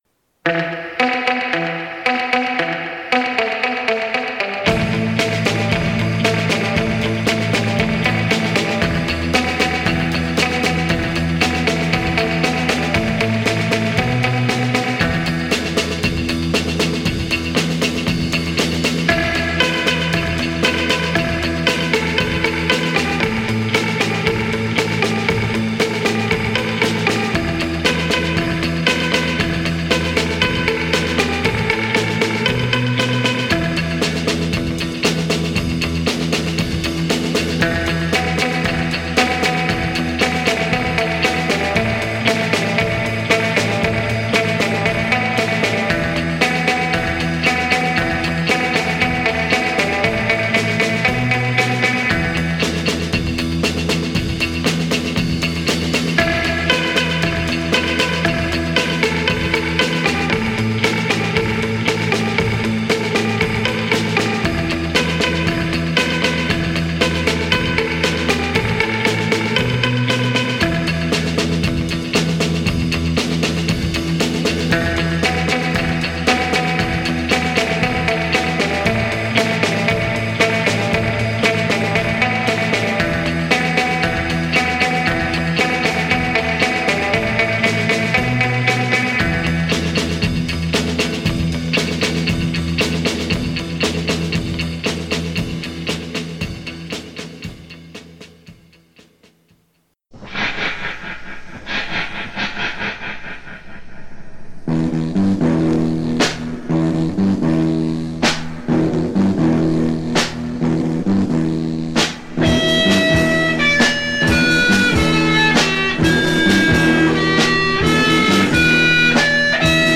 All the best surf rock, psych rock, garage rock, and party rock, from womp bomp a loo bop to rama lama fa fa fa and beyond!